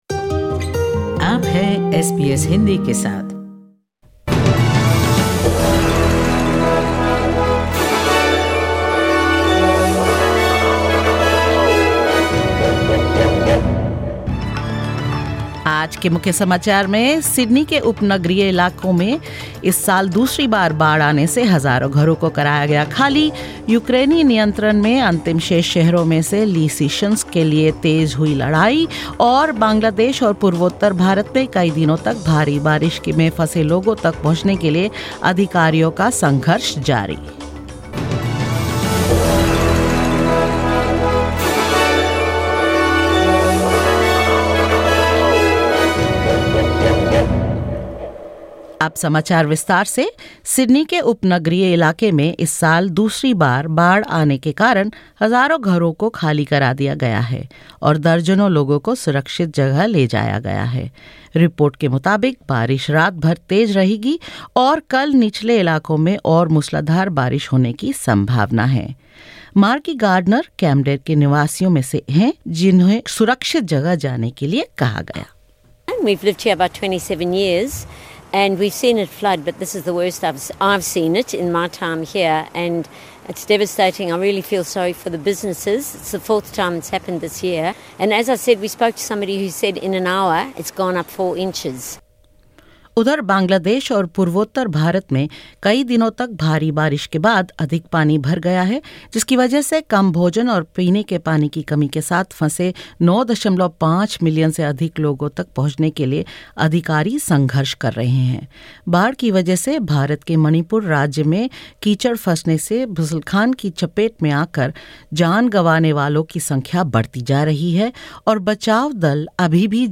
In this latest SBS Hindi bulletin: Thousands of homes evacuated and dozens of people rescued, as water levels rise in Sydney's suburbs; Flooding continues across Bangladesh and northeastern India while mudslides make rescue operations difficult; Ash Barty named Person Of The Year at the NAIDOC Awards and more.